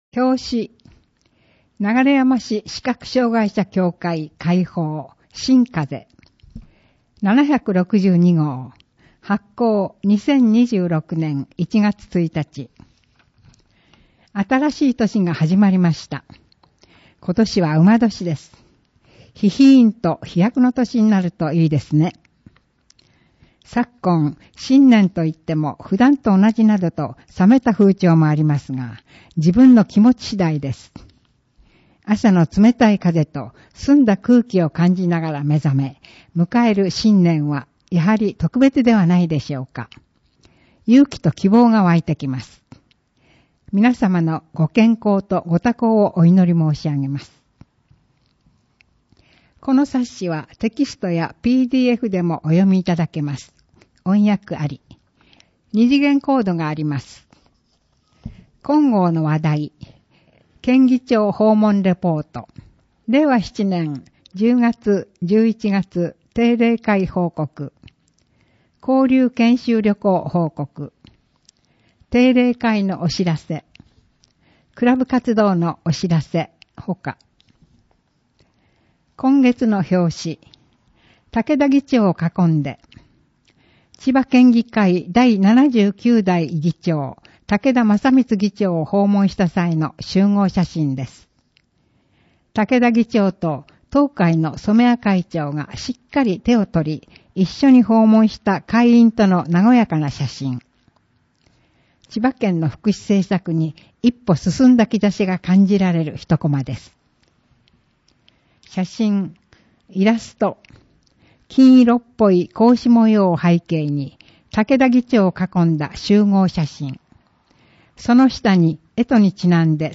※PDF版では本号で使用した写真やイラスト等の説明書きを掲載しています。音訳と合わせてお楽しみください。